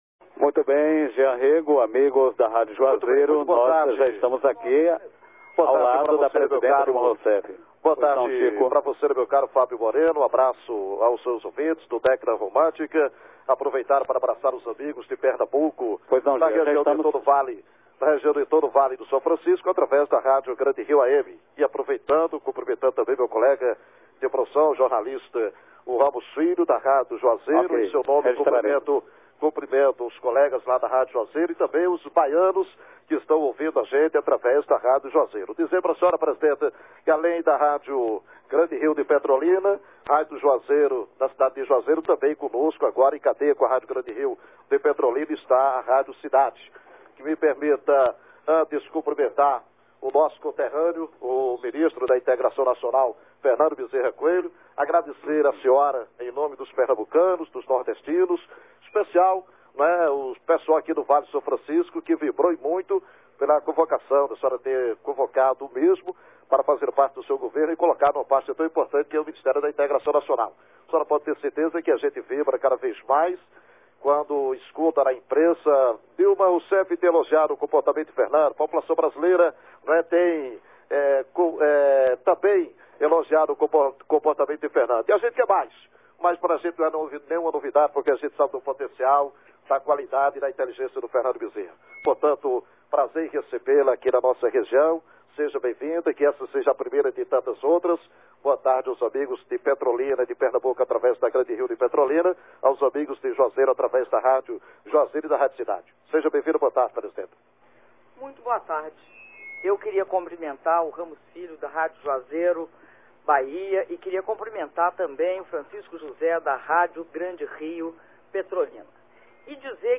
Áudio da entrevista coletiva concedida pela Presidenta da República, Dilma Rousseff, às rádios Grande Rio AM (Petrolina/PE) e Juazeiro AM (Juazeiro/BA) (27min42s)